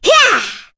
Cecilia-Vox_Attack4_kr.wav